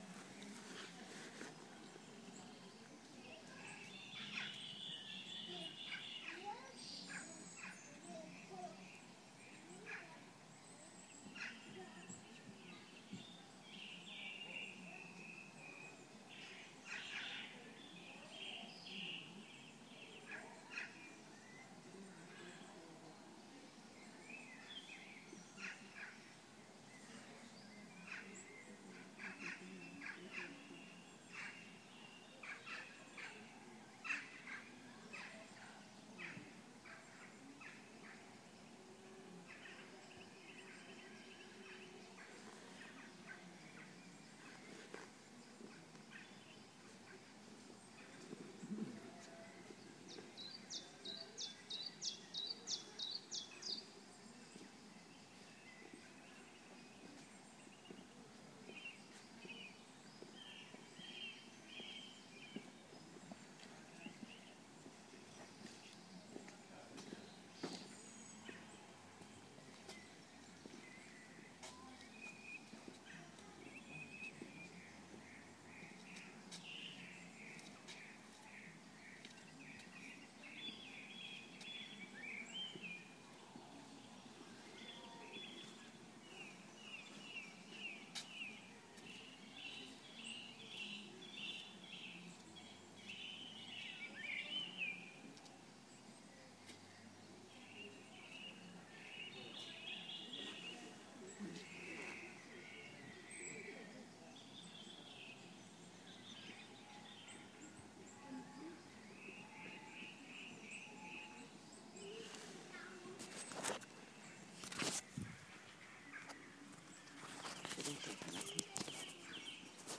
Tatton Park Biennial
Having a sit down, listening to the birds and passing traffic